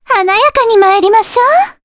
Cassandra-Vox_Skill1_jp.wav